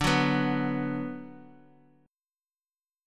D6 Chord
Listen to D6 strummed